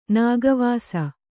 ♪ nāga vāsa